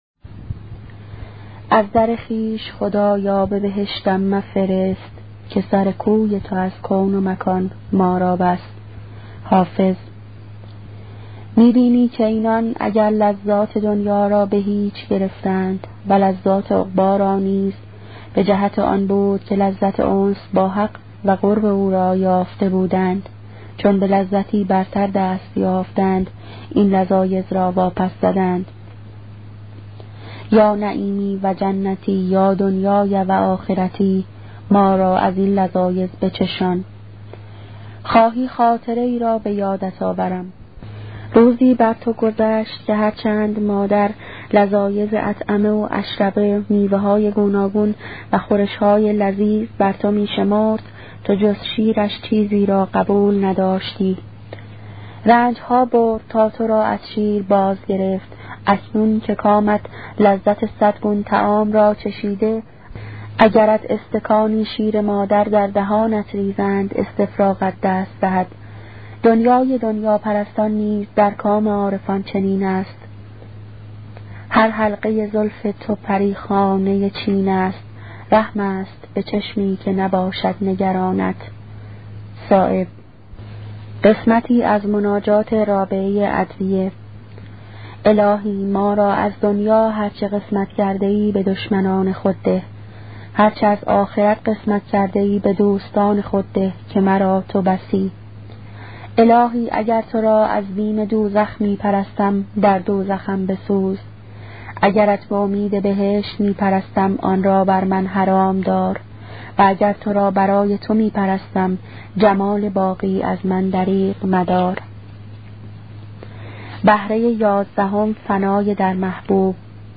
کتاب صوتی عبادت عاشقانه , قسمت چهاردهم